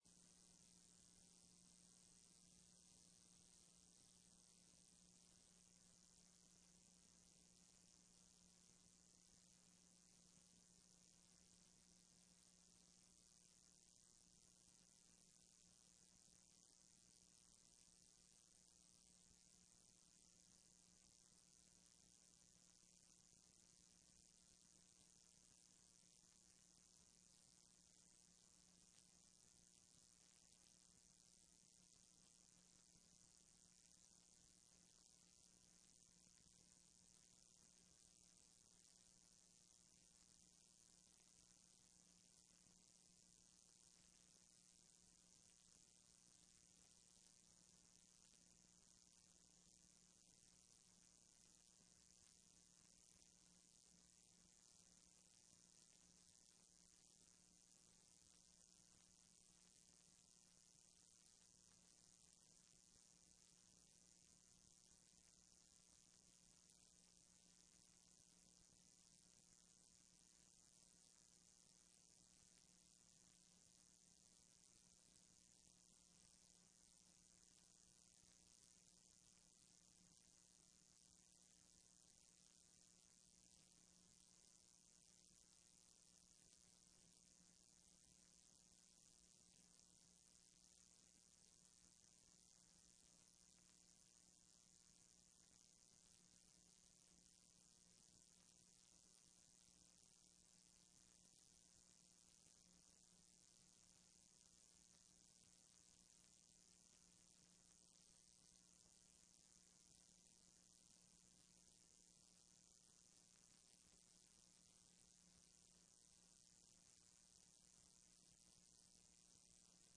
TRE-ES sessão do dia 11/11/15